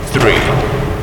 announcer_begins_3sec.mp3